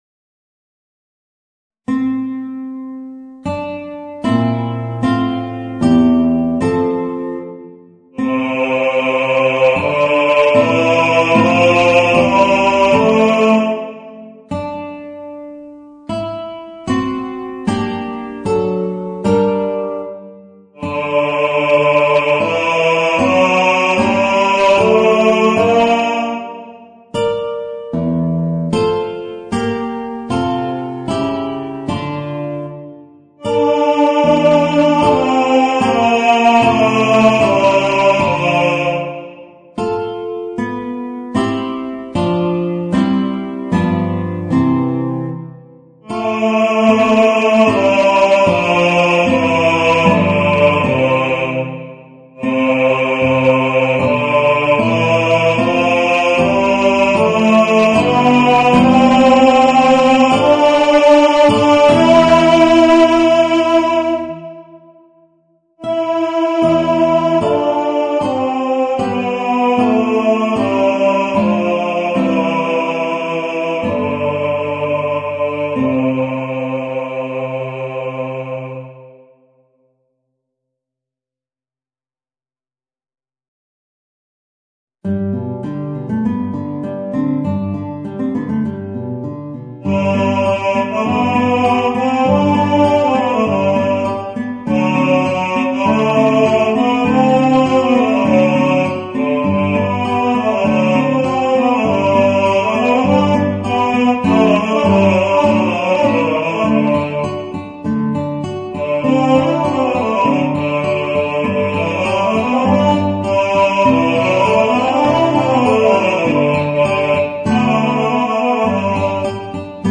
Voicing: Guitar and Baritone